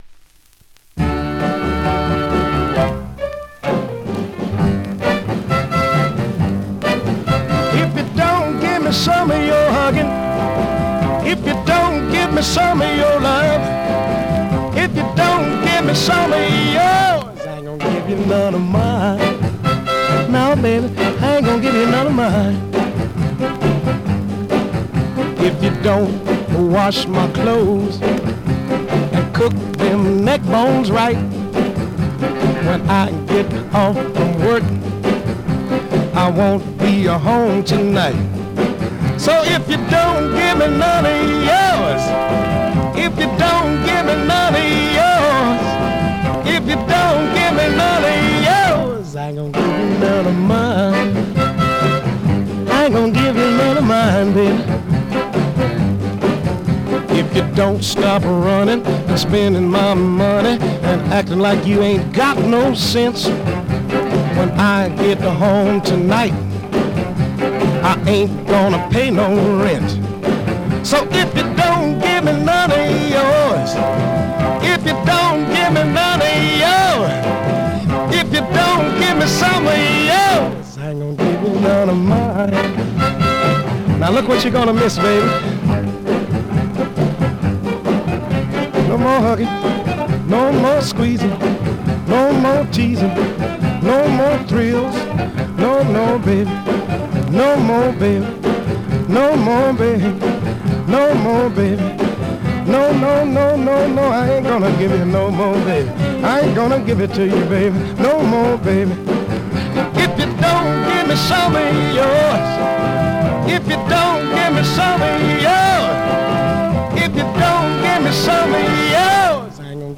R&B, MOD, POPCORN , SOUL